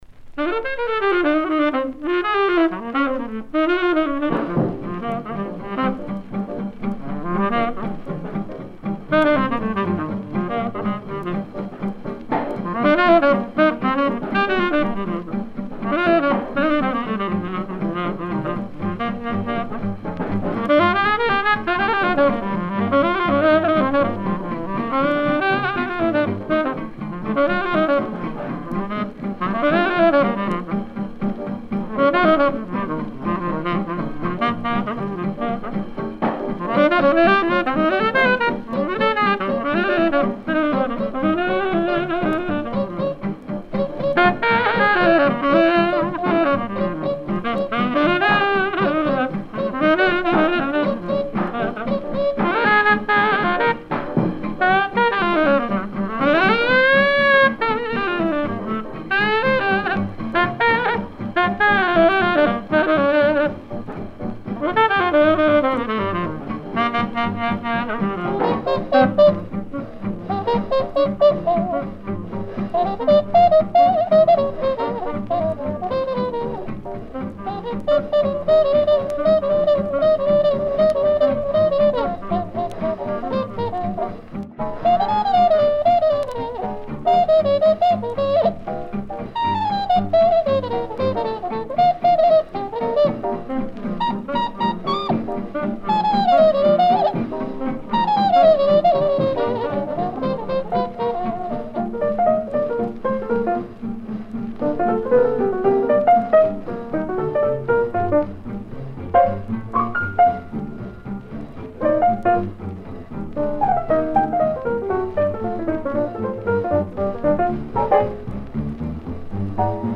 Recorded in New York.
tenor sax
guitar
Trumpet
piano
/ drums.